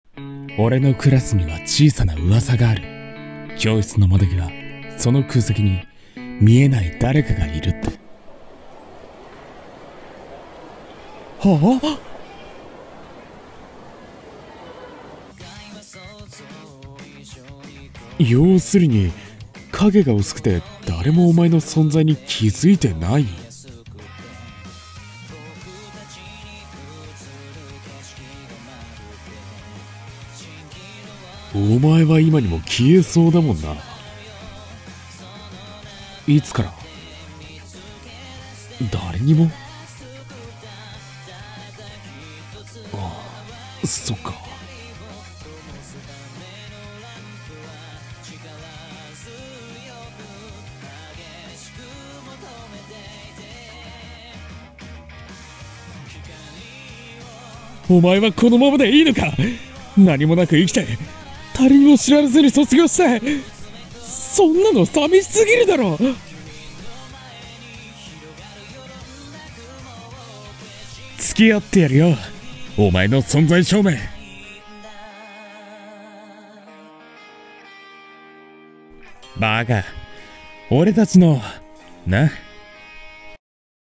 【アニメCM風声劇】昨日より、少しだけ。